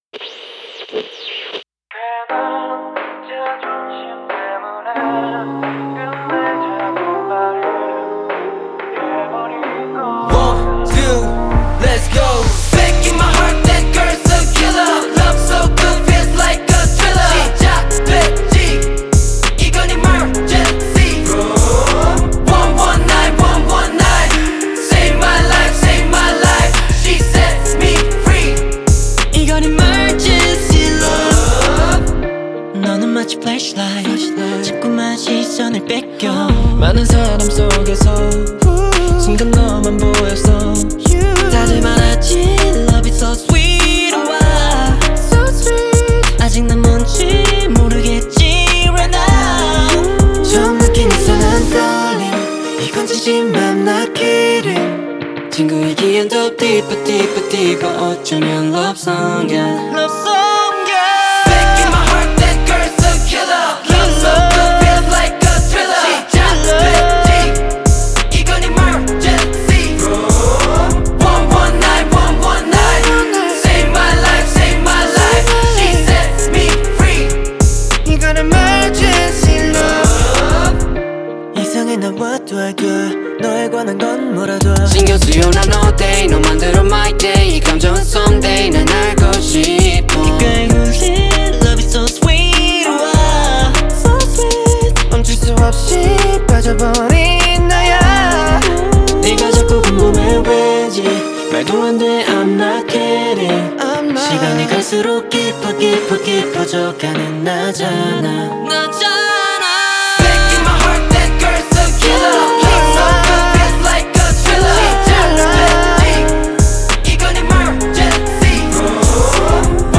• Жанр: K-pop